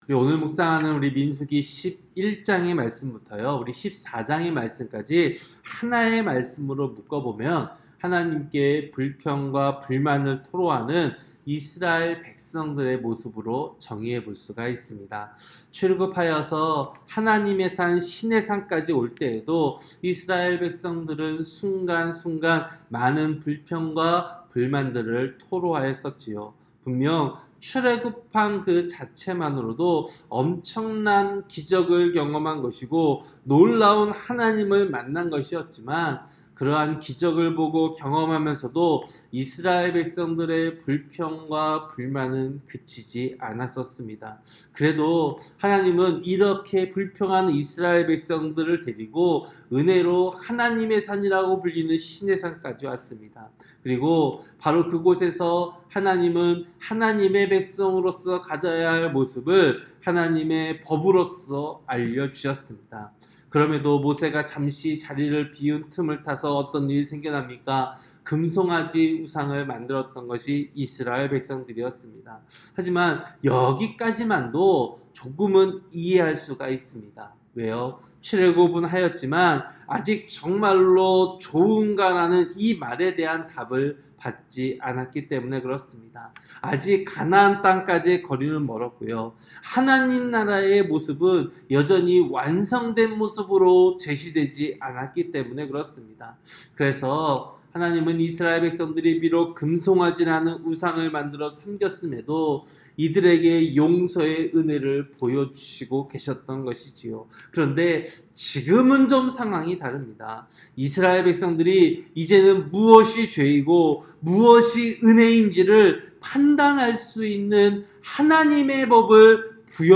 새벽설교-민수기 11장